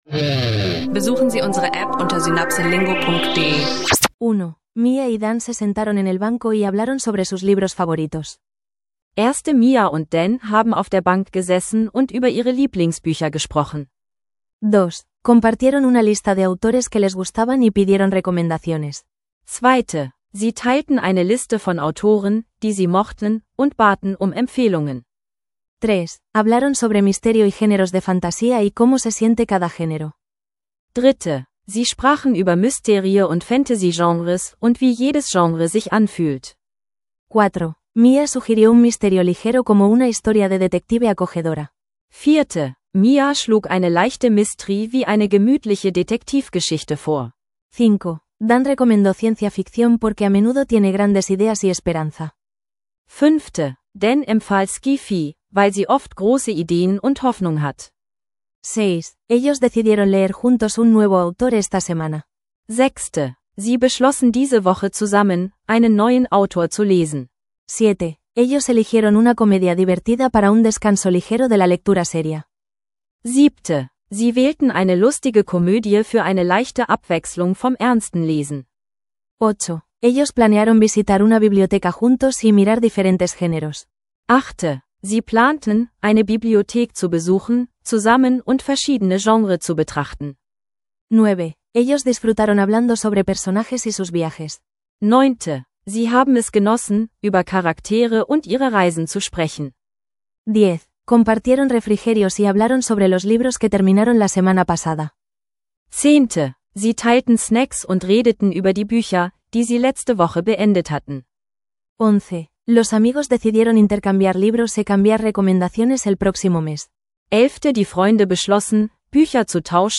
Eine leichte, freundliche Diskussion über Bücher, Autoren und Genres plus eine vertiefte Perspektive auf Diplomatie – perfekt für Spanisch lernen auf dem Weg.